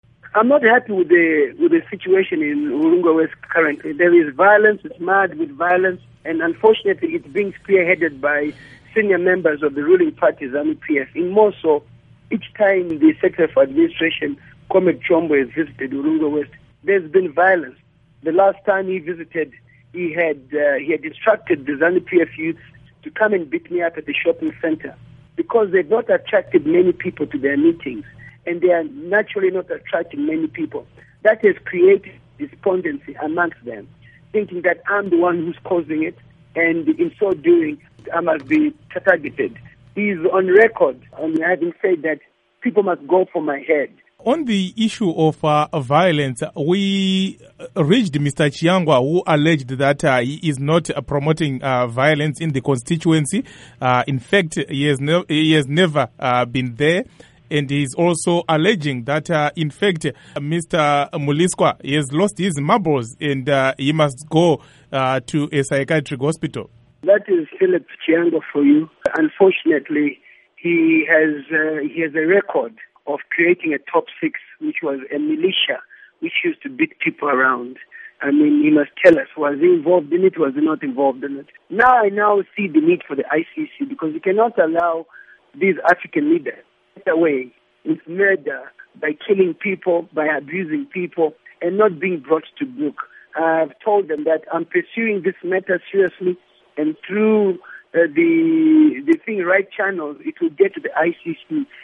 Interview With Temba Mliswa on Alleged Zanu PF Violence